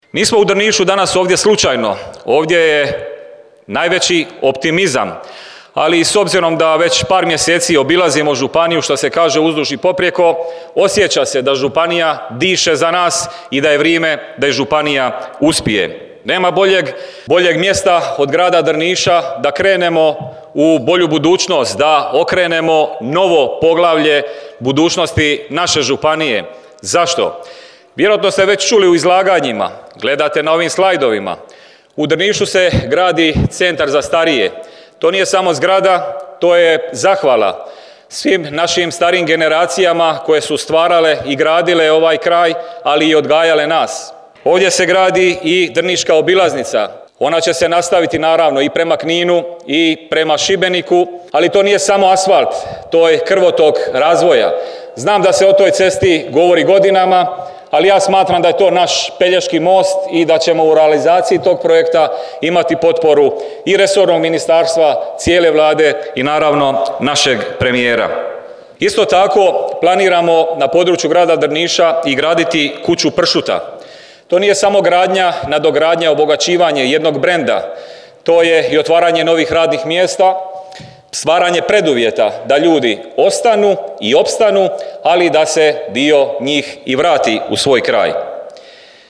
Drniški HDZ proslavio 35. obljetnicu osnutka te održao predizborni skup